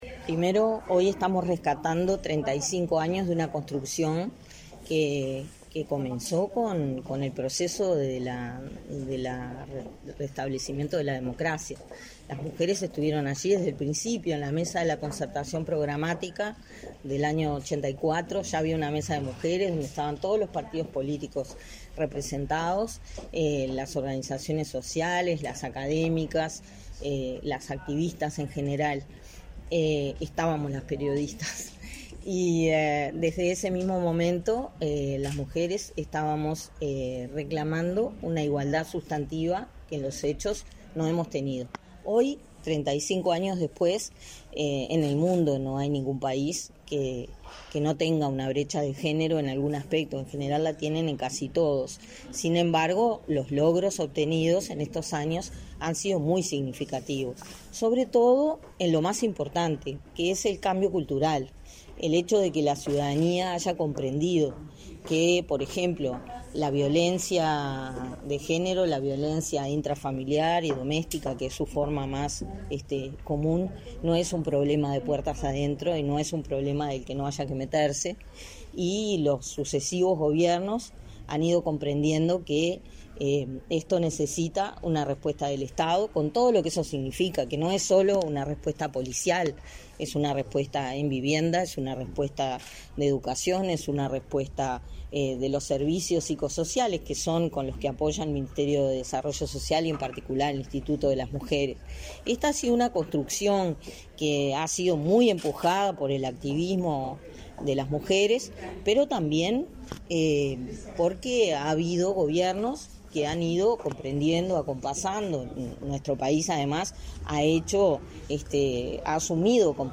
Declaraciones a la prensa de la directora de Inmujeres, Mónica Bottero
Declaraciones a la prensa de la directora de Inmujeres, Mónica Bottero 22/08/2022 Compartir Facebook X Copiar enlace WhatsApp LinkedIn Tras participar en el acto por los 35 años del Instituto Nacional de las Mujeres (Inmujeres), del Ministerio de Desarrollo Social (Mides), su directora, Mónica Bottero, efectuó declaraciones a la prensa.